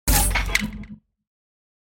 دانلود صدای ربات 78 از ساعد نیوز با لینک مستقیم و کیفیت بالا
جلوه های صوتی